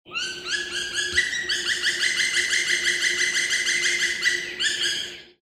Der Schwarzspecht
Laut schallt ein Klopfen durch den Wald, wie ein Trommelwirbel auf Holz.
Schwarzspecht_audio.mp3